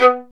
Index of /90_sSampleCDs/Roland L-CD702/VOL-1/STR_Violin 1-3vb/STR_Vln3 _ marc